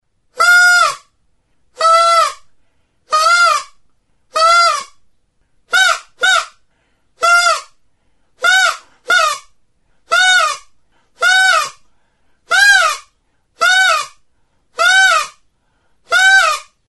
Aerophones -> Reeds -> Single Free
Recorded with this music instrument.